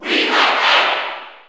Category: Crowd cheers (SSBU) You cannot overwrite this file.
Ike_Cheer_English_SSB4_SSBU.ogg